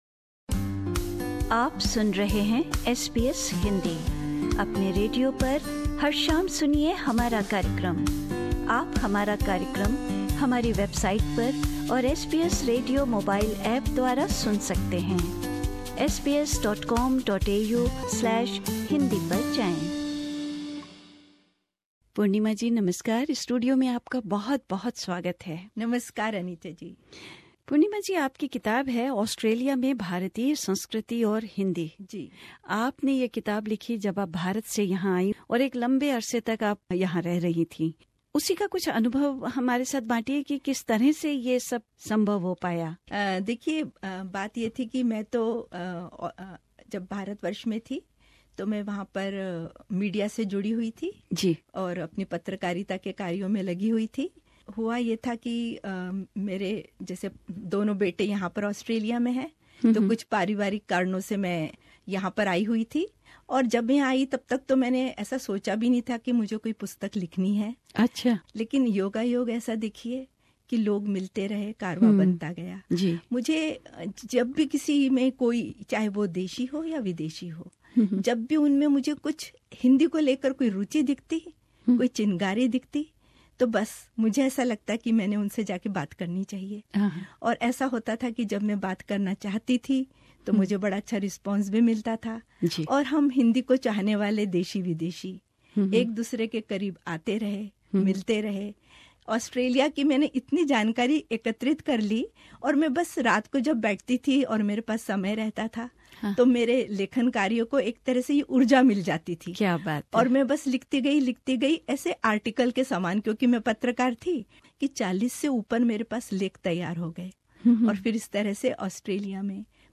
बातचीत में